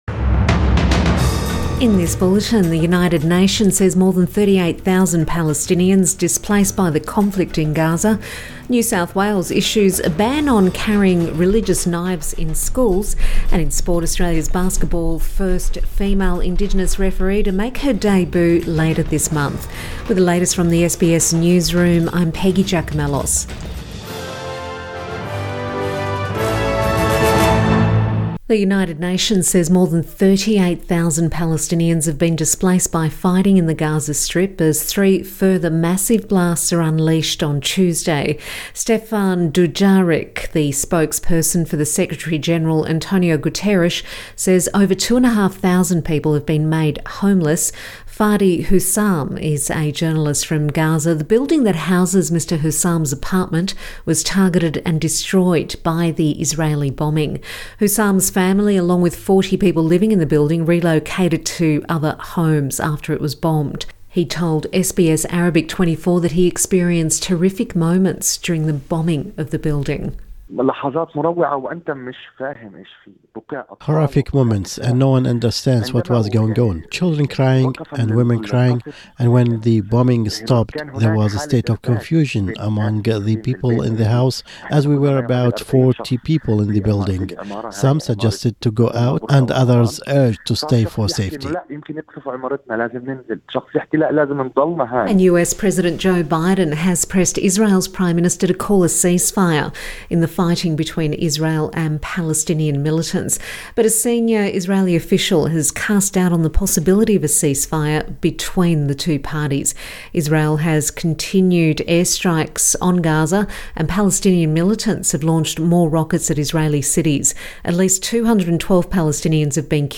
Midday bulletin 18 May 2021